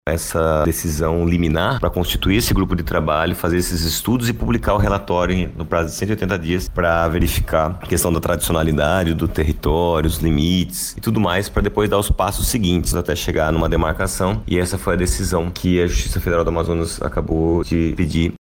O procurador da República, Fernando Soave, explica o que determina a ação da Justiça e os próximos passos a serem adotados por parte do órgão indígena.